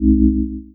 When another avatar enters a player's bubble, The bubble visualization will appear, a soft tone will play, and the "Bubble" HUD button will flash.
bubble.wav